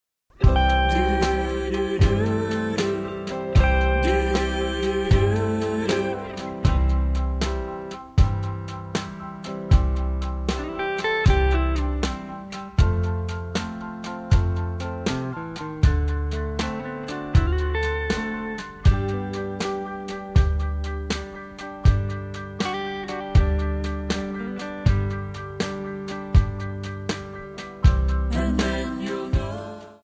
MPEG 1 Layer 3 (Stereo)
Backing track Karaoke
Oldies, Country, 1950s